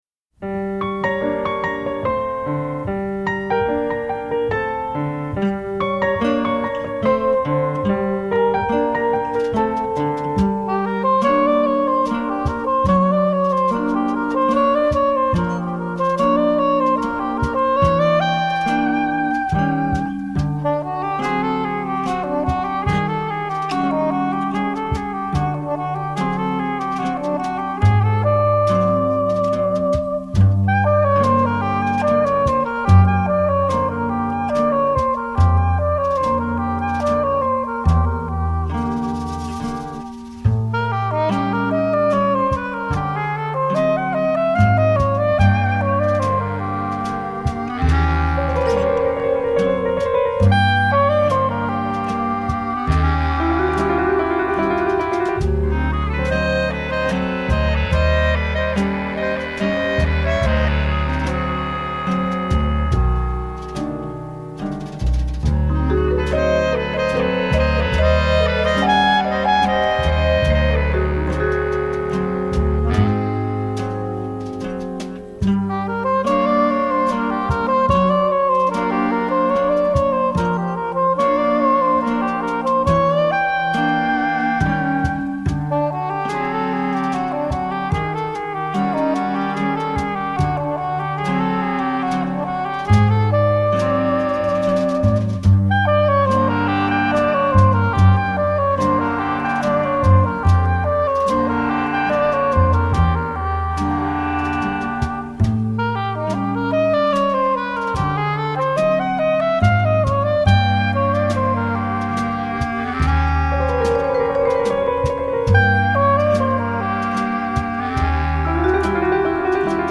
un piano bluesy
piano